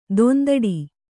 ♪ dondaḍi